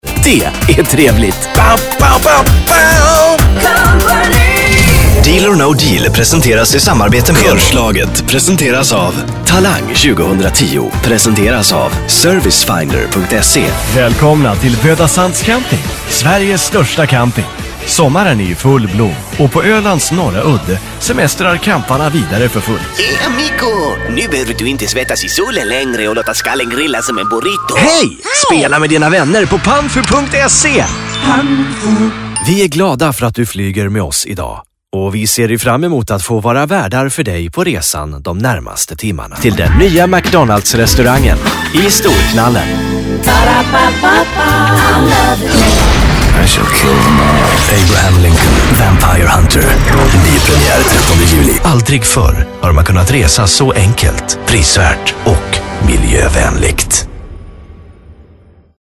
Varied for almost any desire Warm, deep, fun, smooth, high impact, etc.
Kein Dialekt
Sprechprobe: Industrie (Muttersprache):